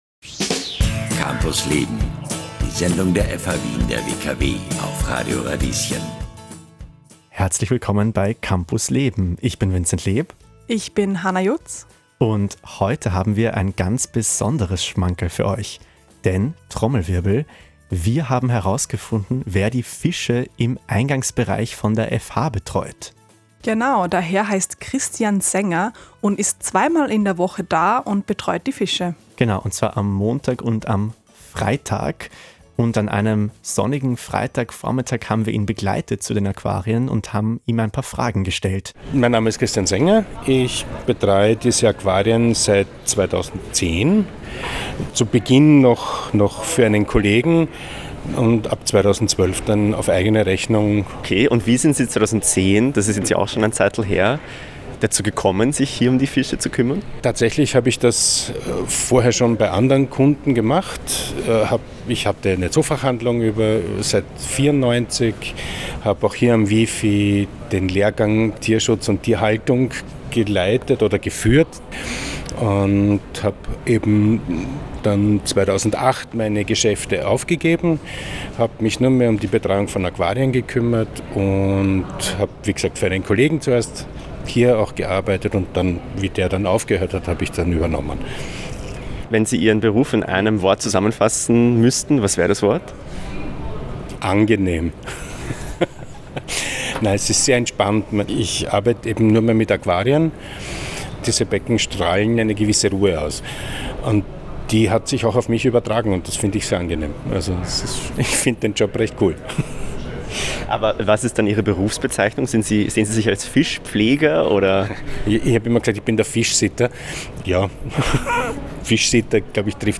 Dieser Podcast ist ein Ausschnitt aus der Campus Leben-Radiosendung vom 1. Mai 2024.